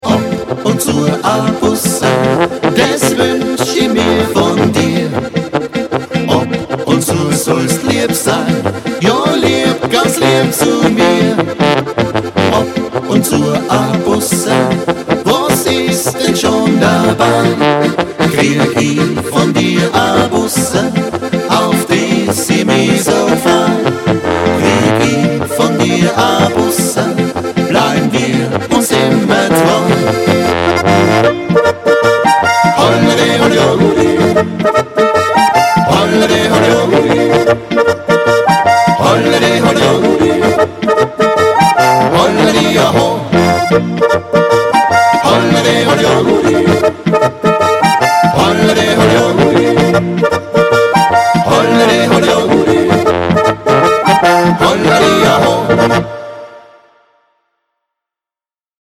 VOLKSTÜMLICH